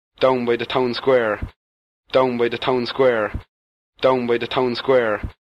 Lack of AU-fronting with local Carlow speaker
Carlow_AU-NoFronting.mp3